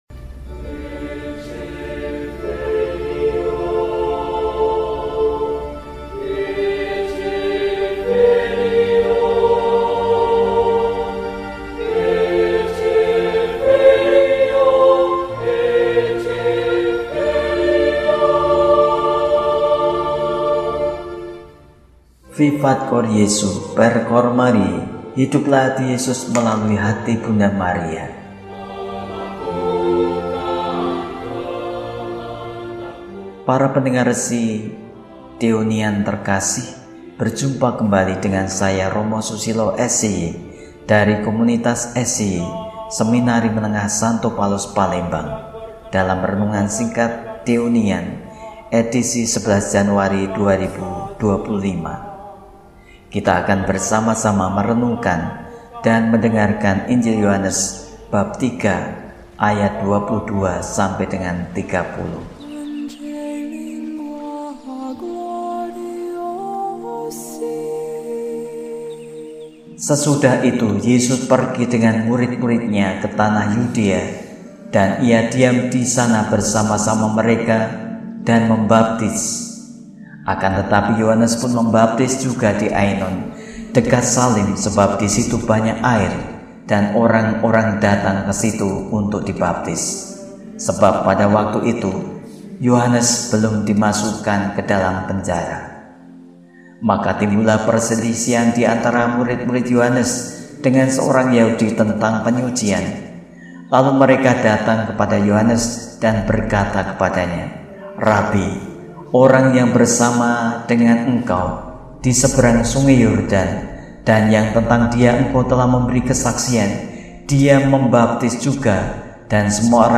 Sabtu, 11 Januari 2025 – Hari Biasa Sesudah Penampakan Tuhan – RESI (Renungan Singkat) DEHONIAN